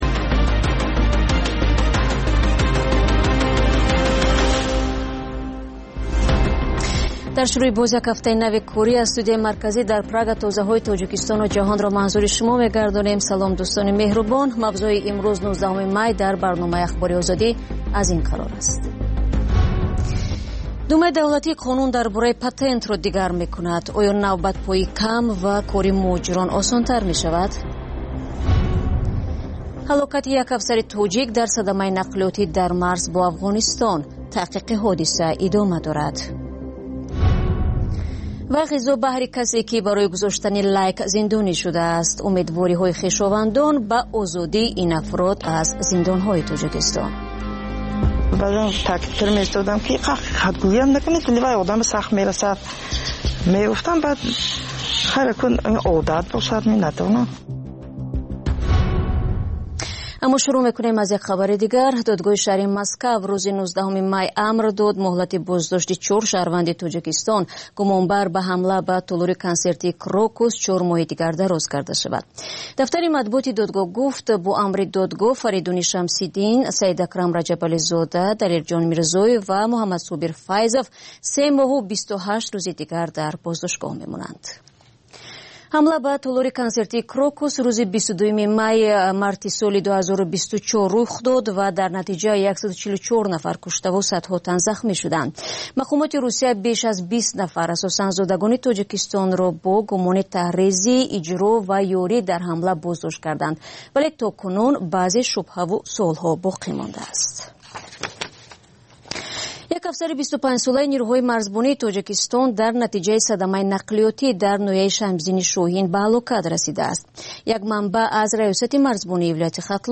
Пахши зинда
Маҷаллаи хабарӣ